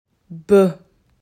• Laut des Buchstabens „b“:
b-laut.m4a